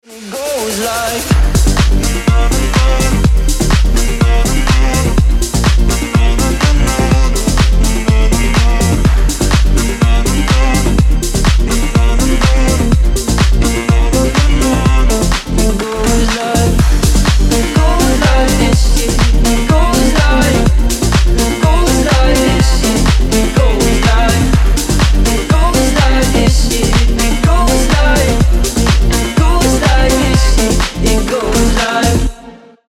Стиль: house